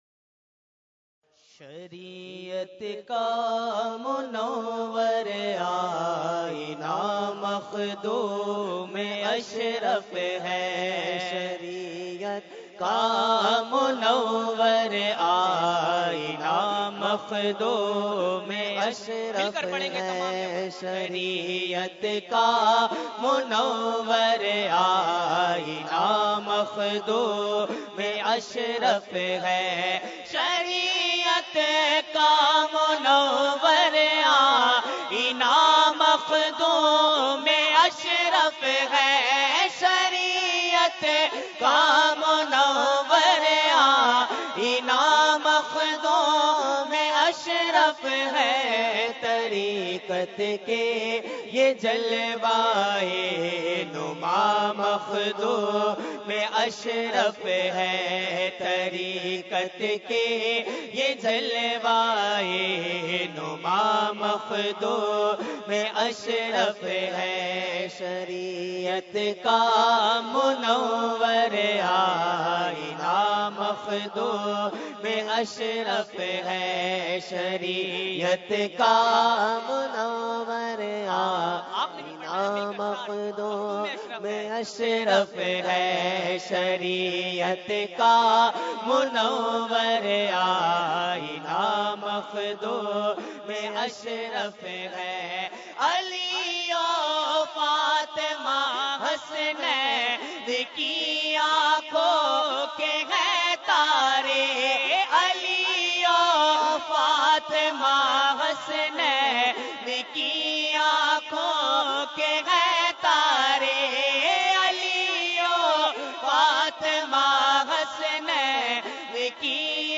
Category : Manqabat | Language : UrduEvent : Urs Makhdoome Samnani 2016